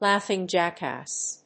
アクセントláughing jáckass